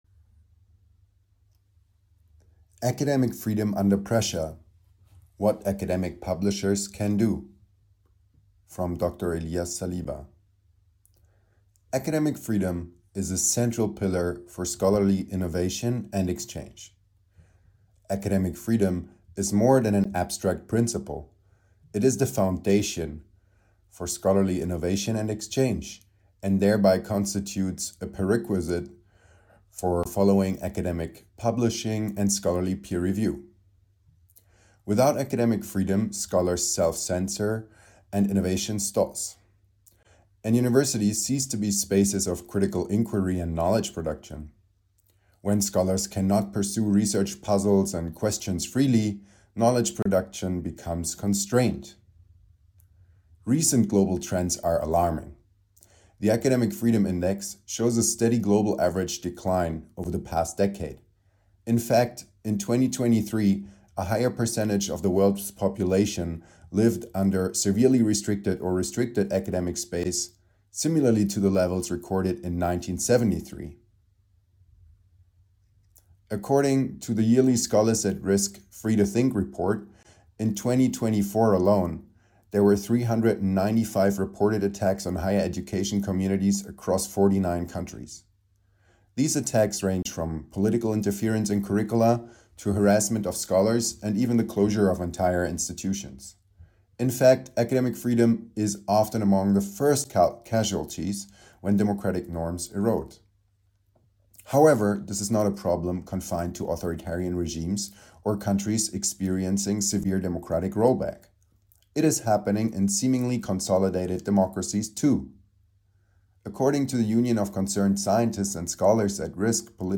Authors’ note: The audio file linked below provides a spoken version of this blog post.